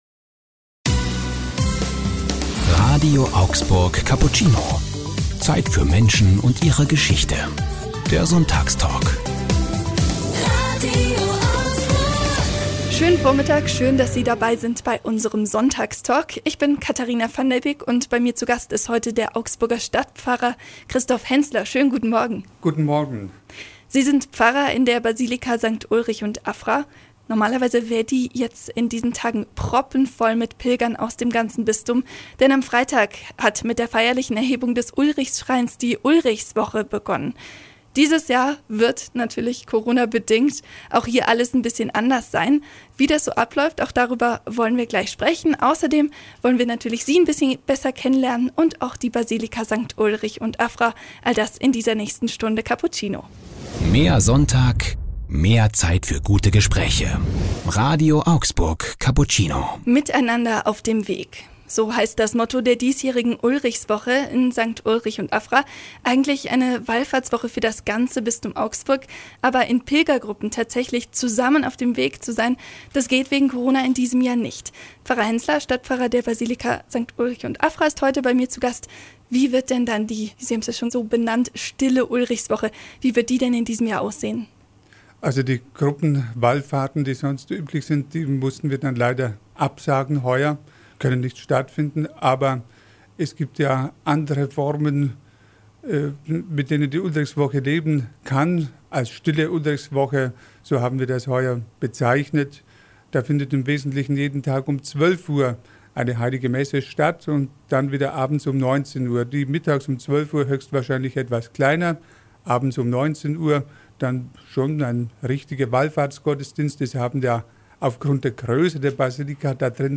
Hier können sie den ganzen Sonntagstalk nachhören: Mehr